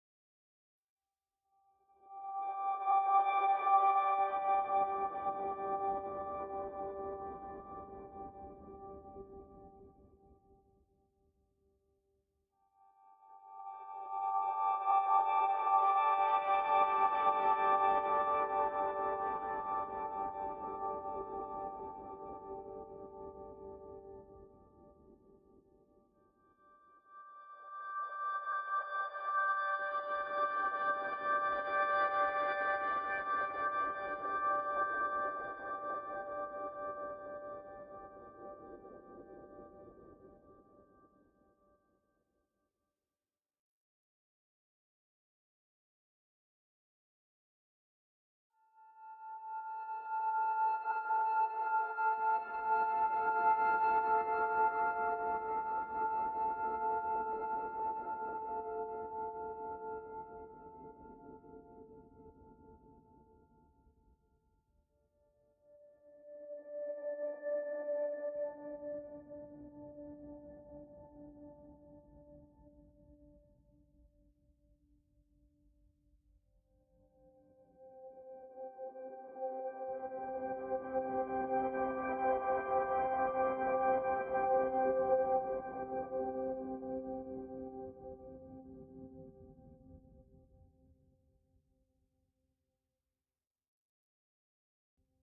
pads